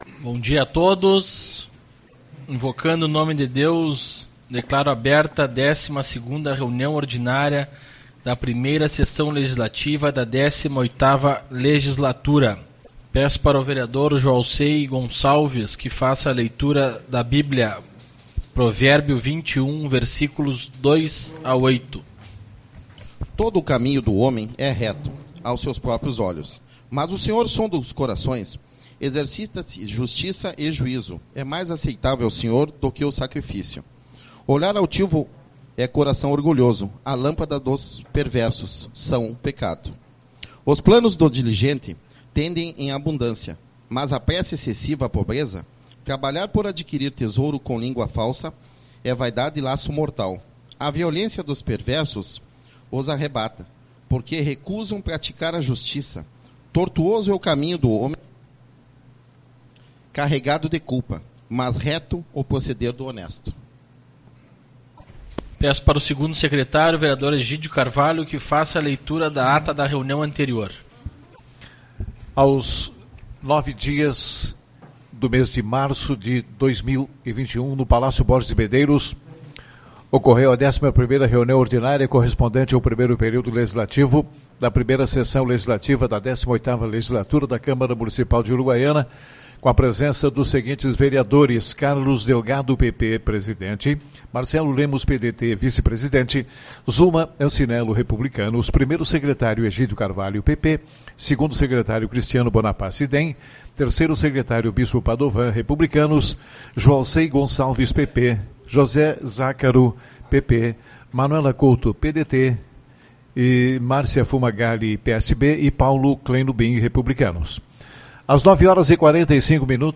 11/03 - Reunião Ordinária